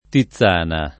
[ ti ZZ# na ]